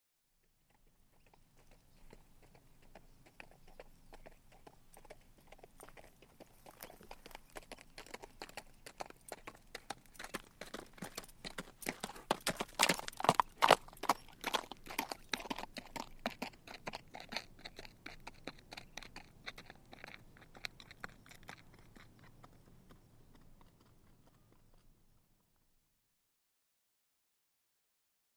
دانلود آهنگ اسب 86 از افکت صوتی انسان و موجودات زنده
جلوه های صوتی
دانلود صدای اسب 86 از ساعد نیوز با لینک مستقیم و کیفیت بالا